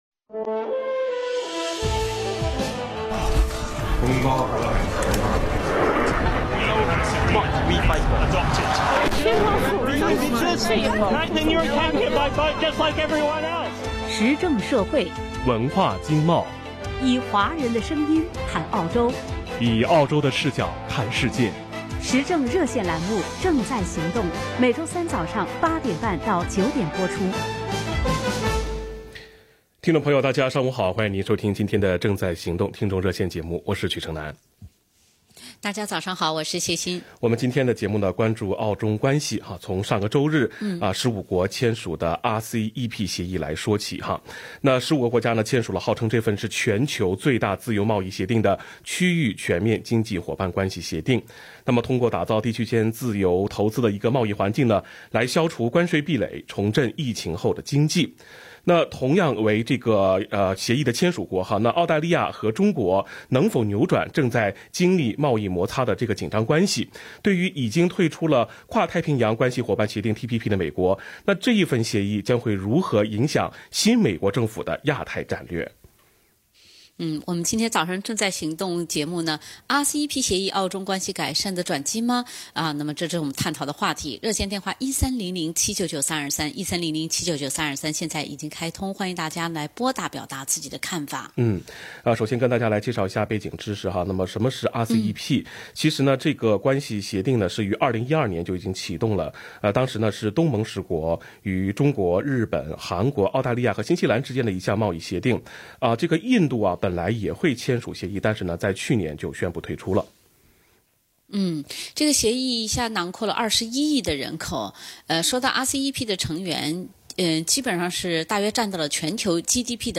（以上为热线听众发言总结，不代表本台立场）。